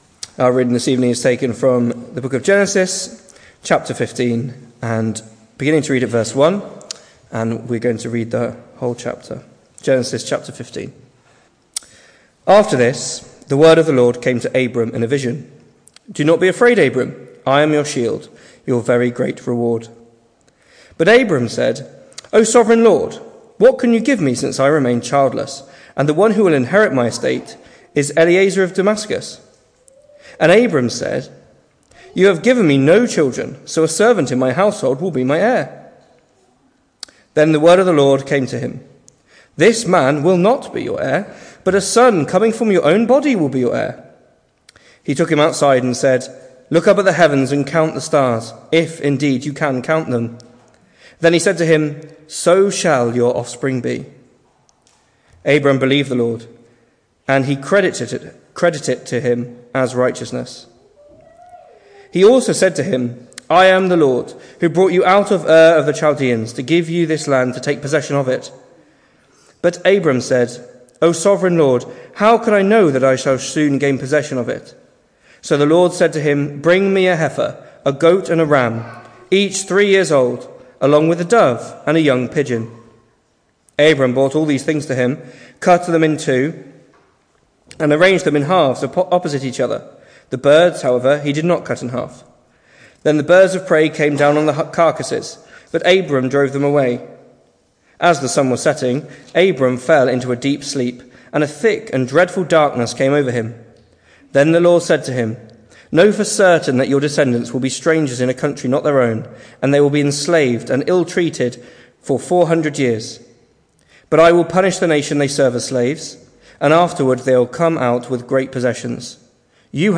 Hello and welcome to Bethel Evangelical Church in Gorseinon and thank you for checking out this weeks sermon recordings.
The 5th of October saw us hold our evening service from the building, with a livestream available via Facebook.